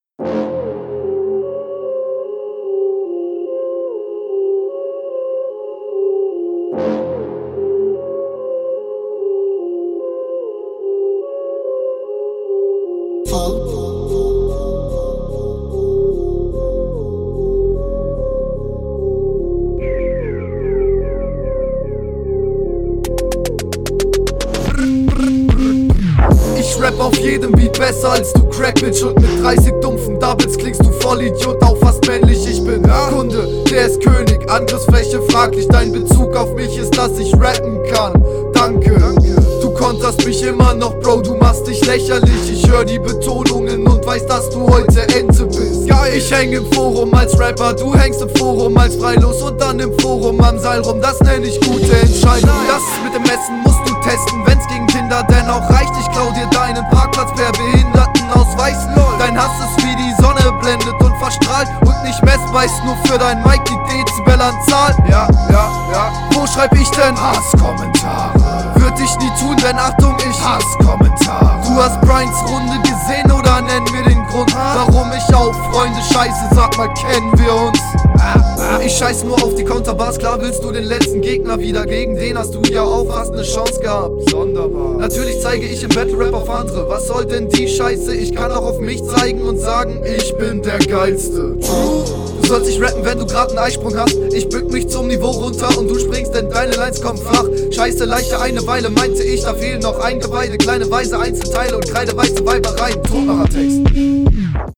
Ok haha die Runde ist super, du verhaspelst dich manchmal zwar ein bisschen und die …